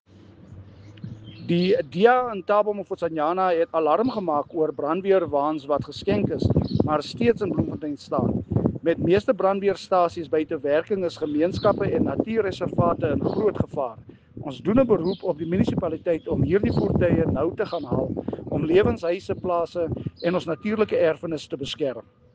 Afrikaans soundbites by Cllr Marius Marais and